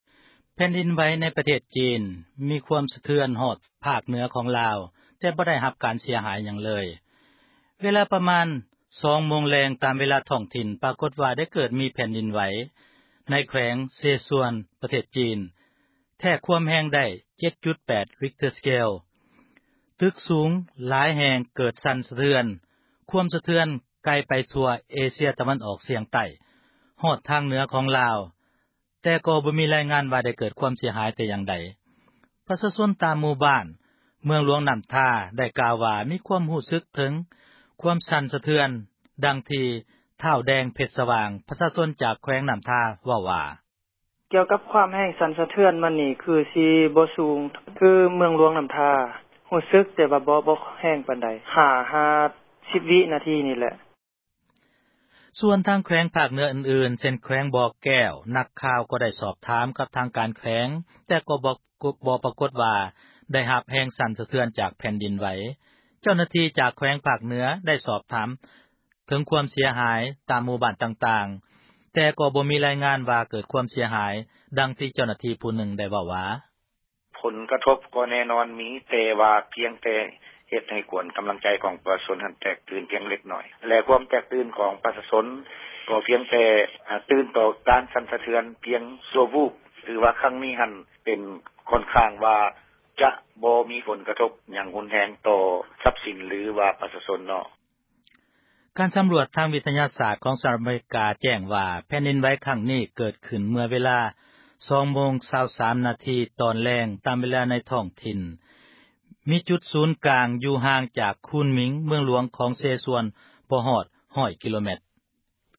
ສຽງແຜ່ນດິນໄຫວ